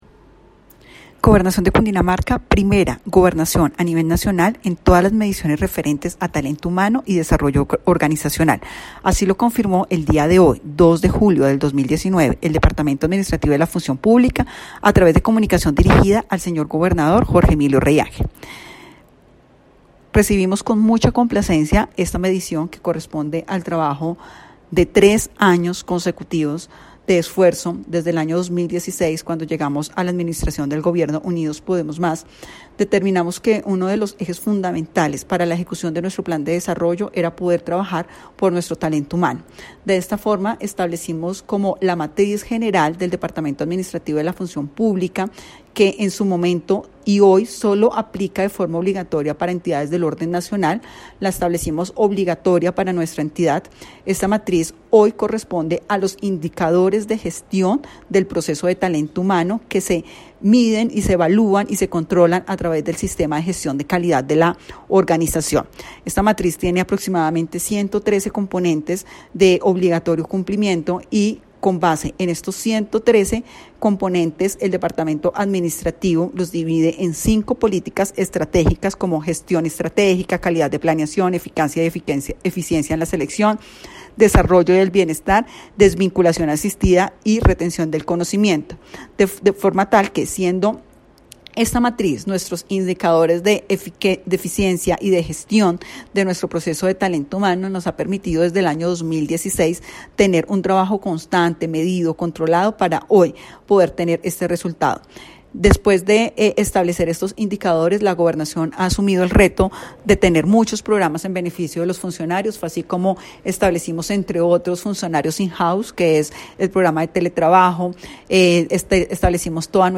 Voz Yolima Mora Salinas, Secretaria de la Función Pública de Cundinamarca.